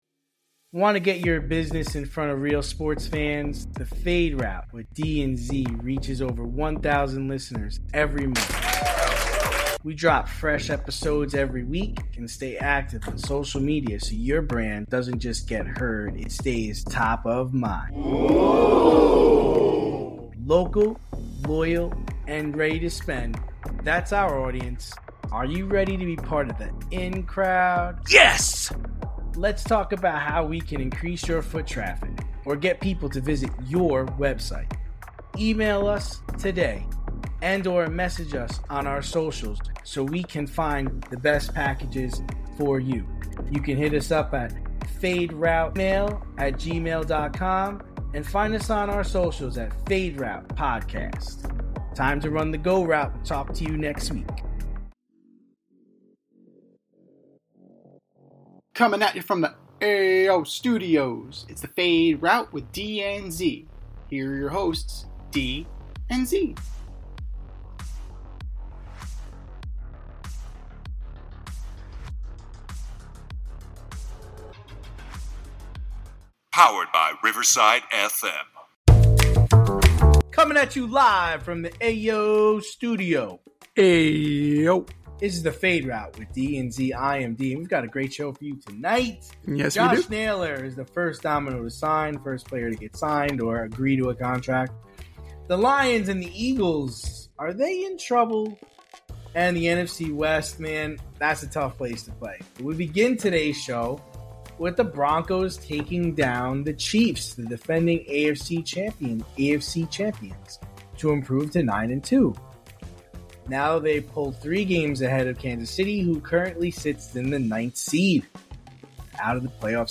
two veteran sports aficionados and lifelong friends